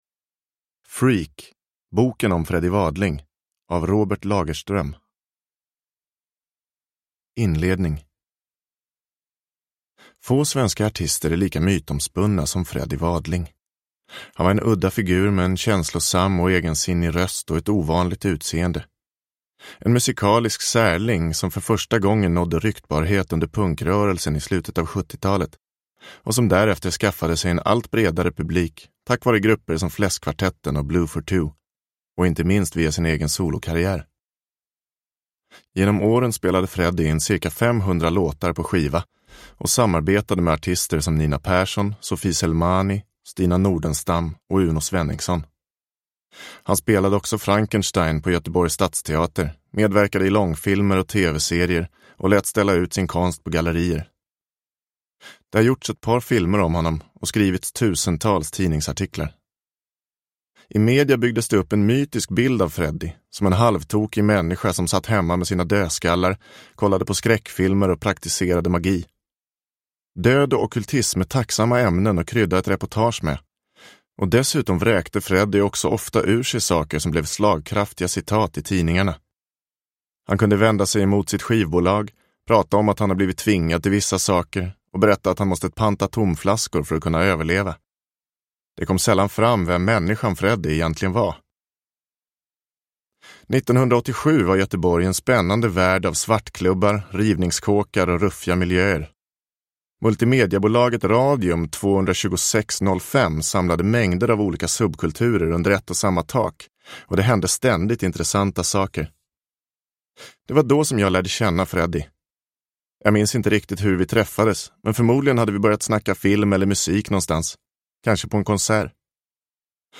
Freak - boken om Freddie Wadling – Ljudbok
Uppläsare: Simon J Berger